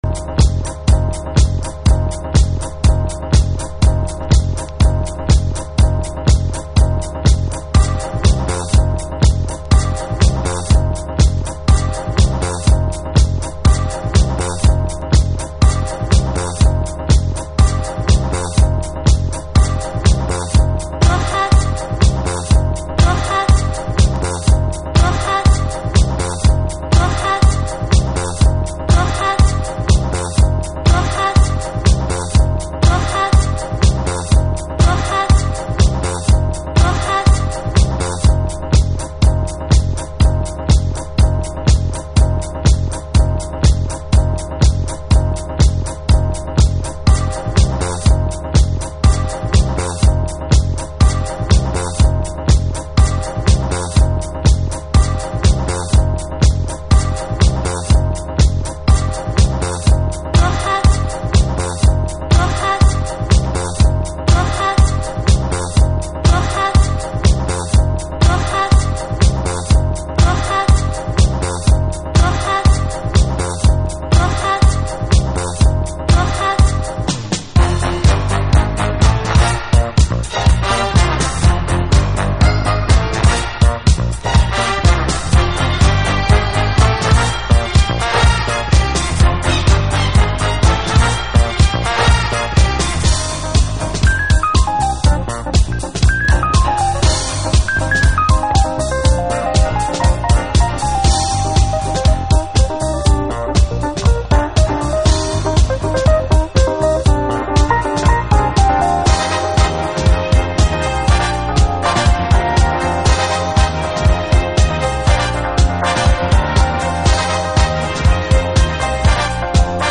Alt Disco / Boogie
脈々と続くリエディットという様式。